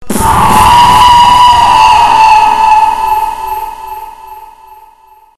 FNa P " 可怕的尖叫3
标签： 尖啸 恐怖 可怕
声道立体声